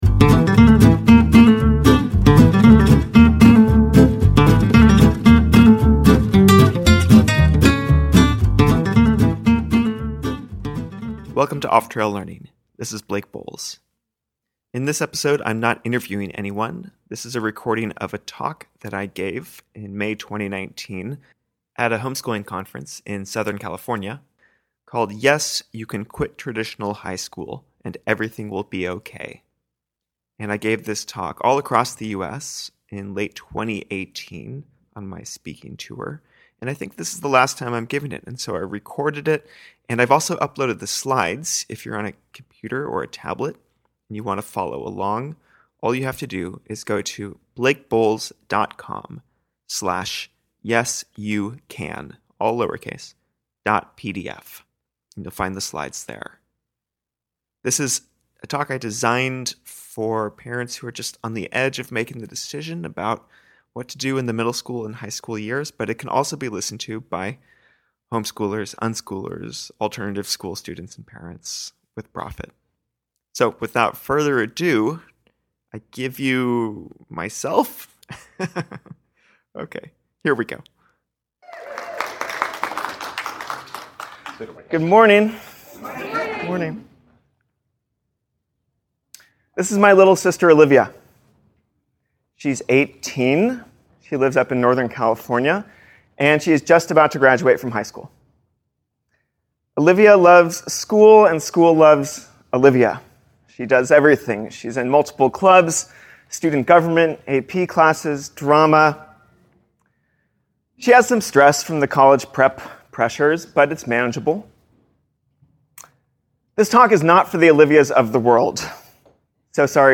recorded live at the CHN Family Expo conference in southern California on May 4th, 2019